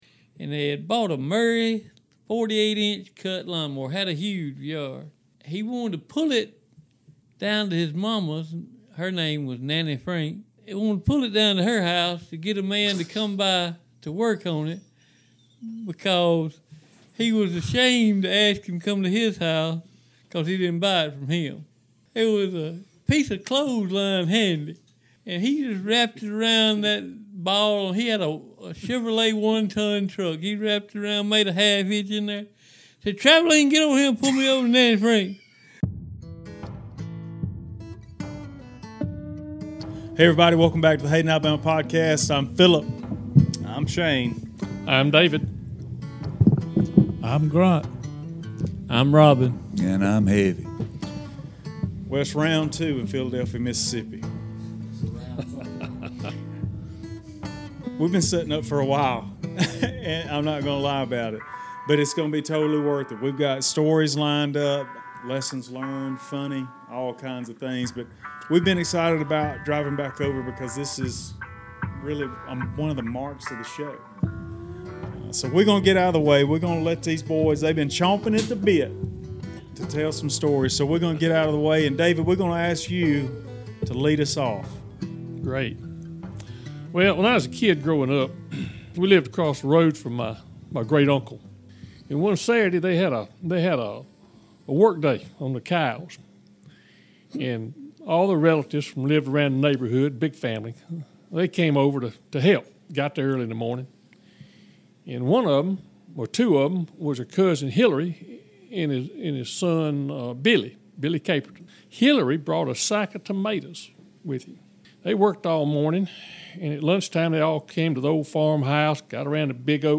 We went back to Philadelphia, Mississippi for another round at the pond-side cabin.
Get ready for some great stories and plenty of laughs.